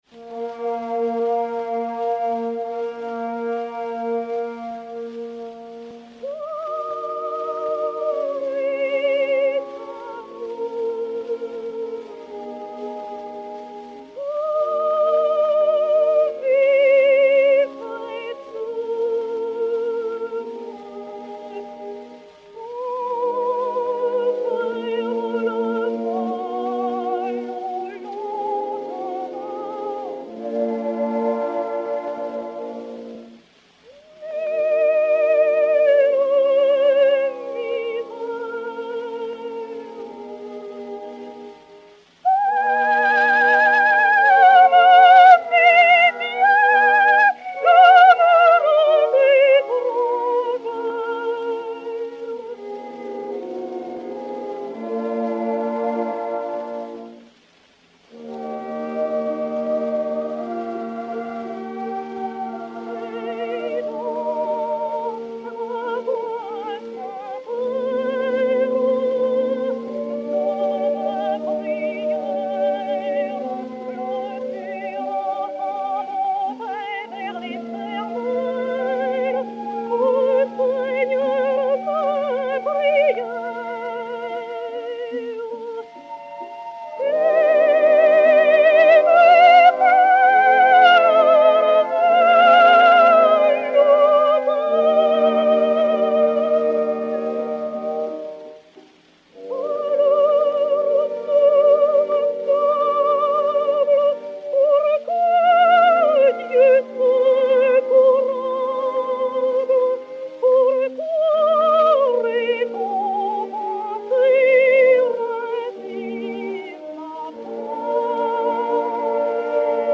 et Orchestre
Odéon 97562, mat. XP 4980, enr. à Paris vers 1912